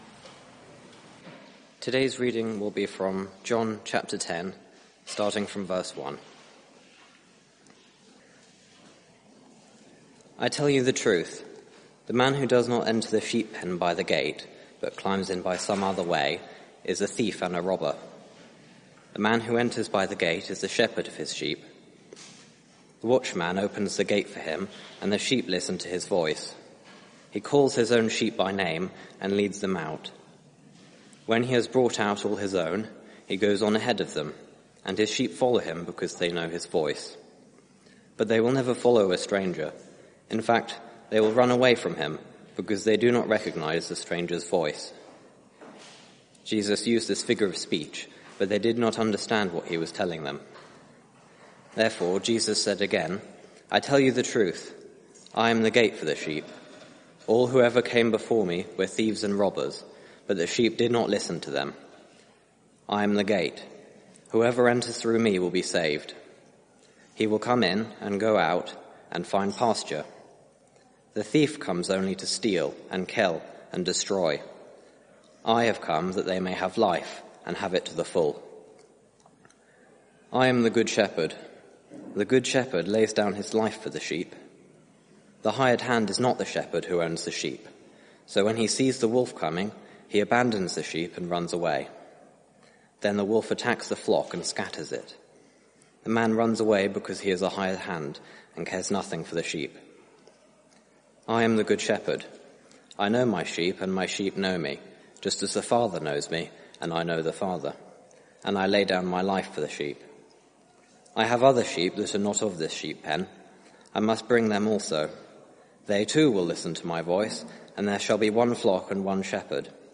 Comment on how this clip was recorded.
Media for 6:30pm Service on Sun 13th Jun 2021 18:30 Speaker